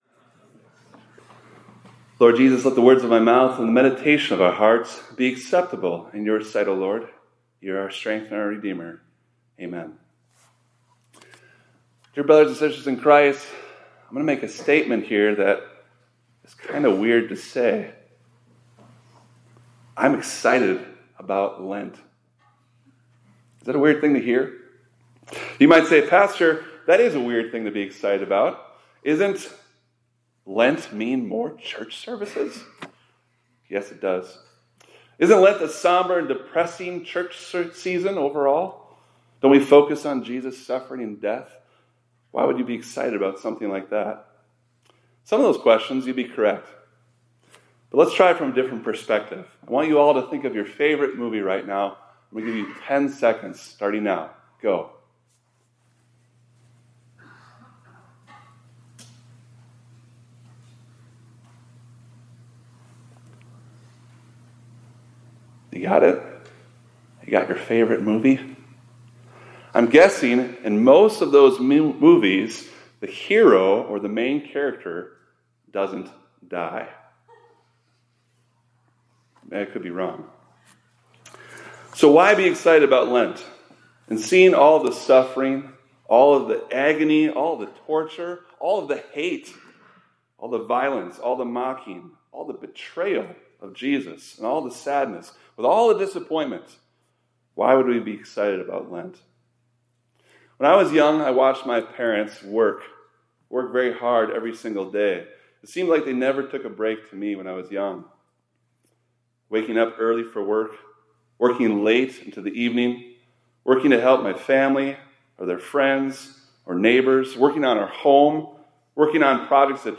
2026-02-11 ILC Chapel — In Lent You See Jesus Working For You – Immanuel Lutheran High School, College, and Seminary